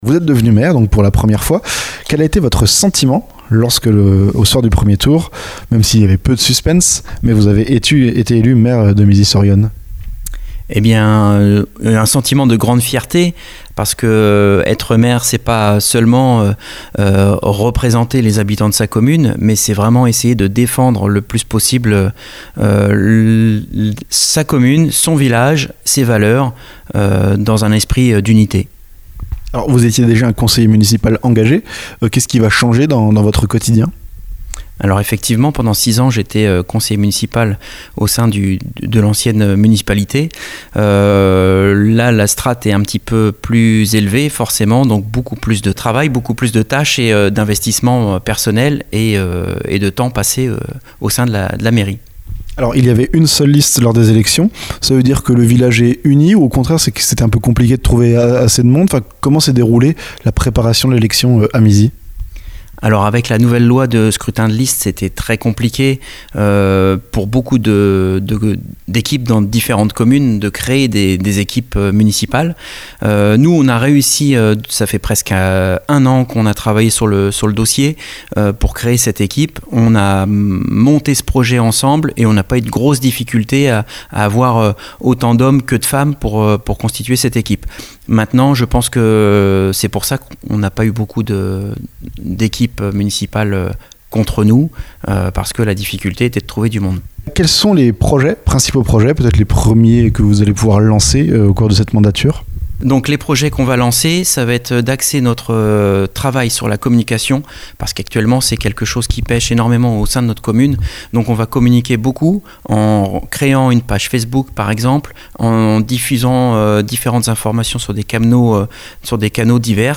Un engagement chronophage et des idées pour faire vivre un village qui se développe. Entretien avec le nouvel élu.